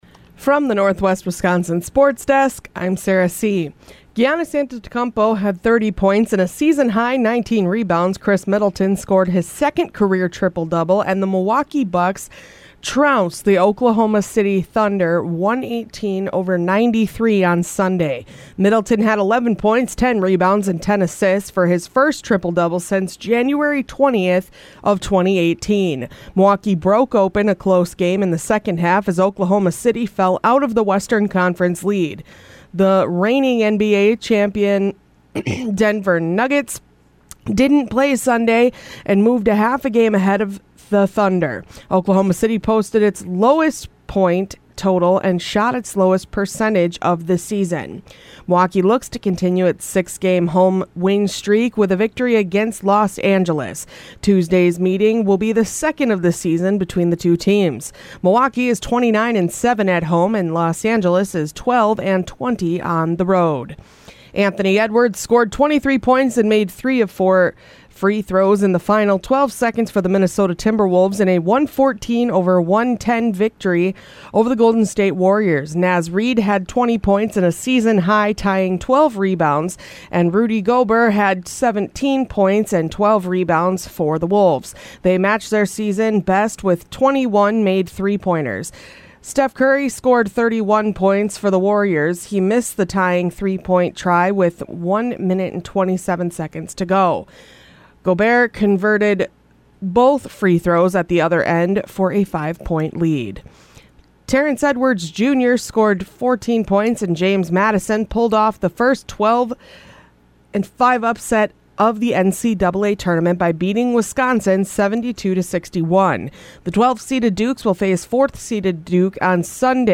Today’s sportscast from the Northwest Wisconsin Sports Desk.